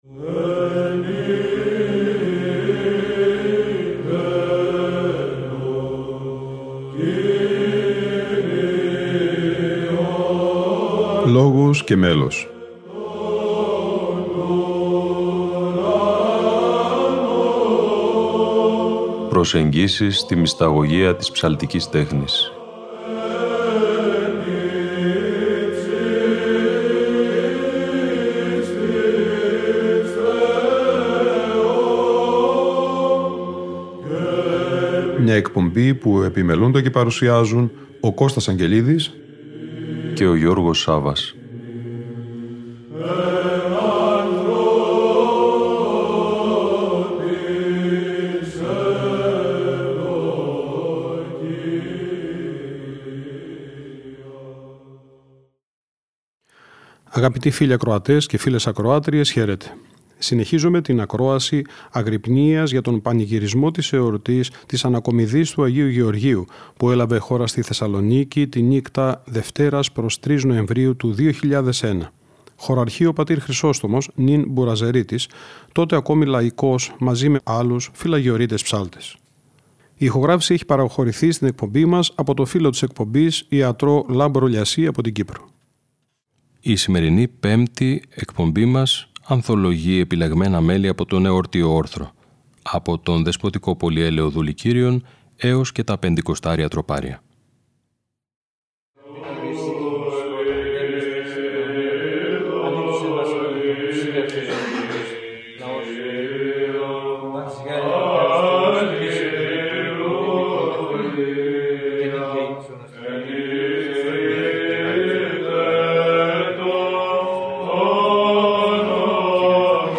Αγρυπνία Ανακομιδής Λειψάνων Αγίου Γεωργίου - Ροτόντα 2001 (Ε΄)